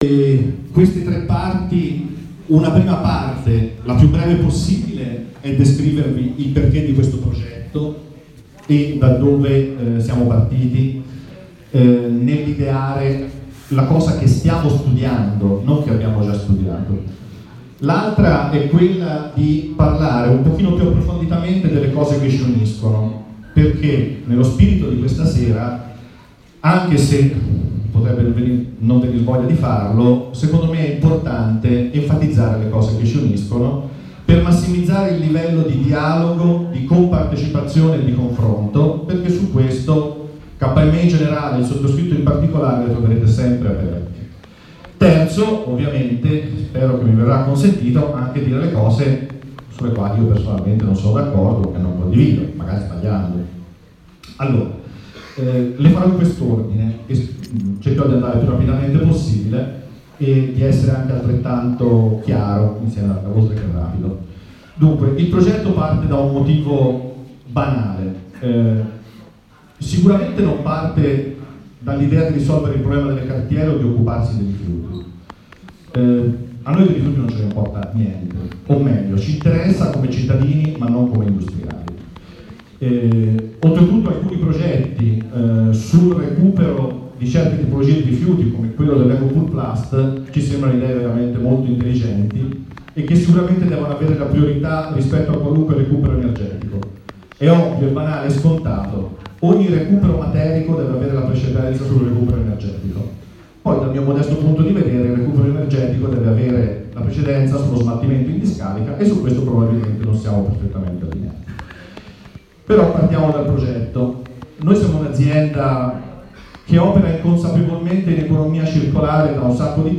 Assemblea La Libellula